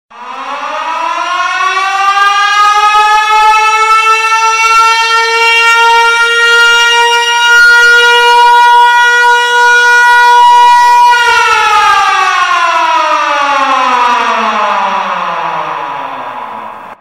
دانلود صدای پیامک 3 از ساعد نیوز با لینک مستقیم و کیفیت بالا
جلوه های صوتی